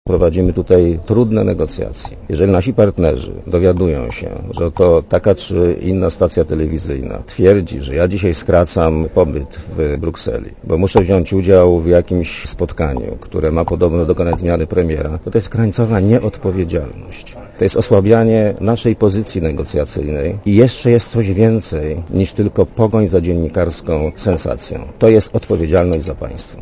Dla Radia Zet mówi premier Leszek Miller (104 KB)